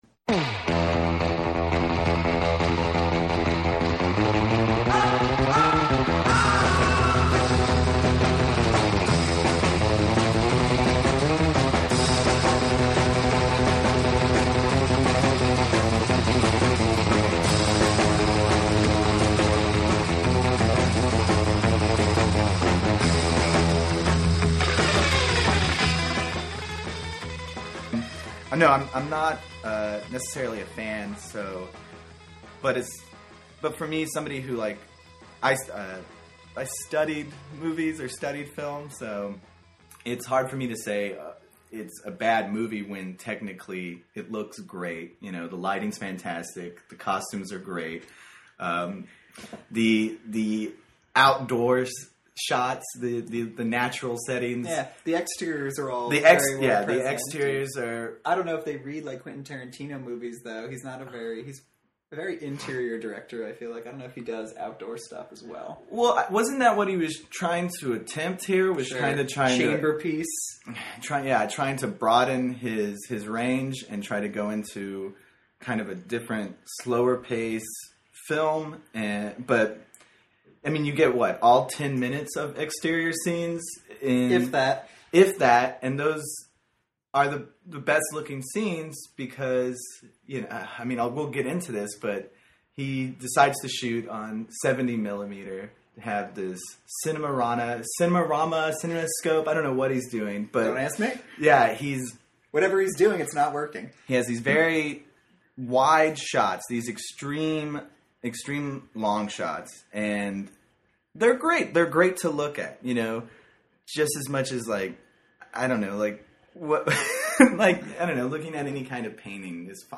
Highlights include a retrospective of Tarantino's work, excellent impressions of QT, and ruminations on the quality of Alamo Drafthouse milkshakes.